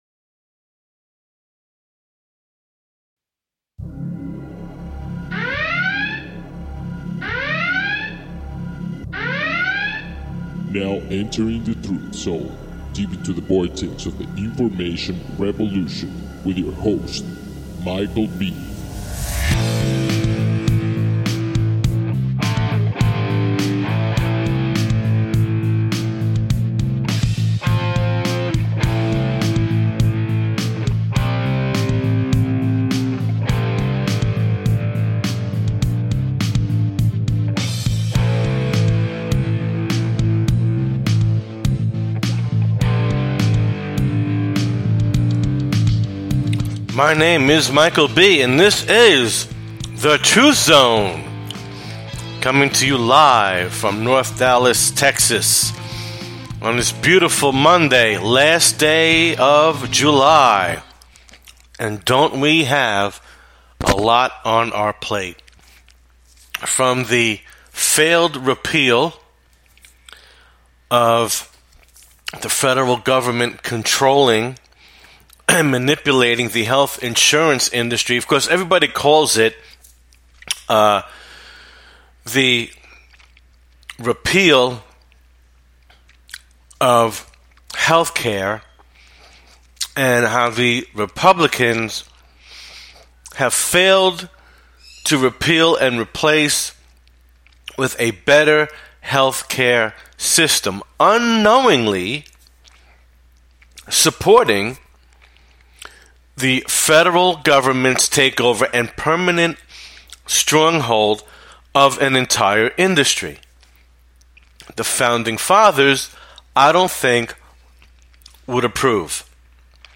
The Truth Zone is in your face radio and not for the weak of heart.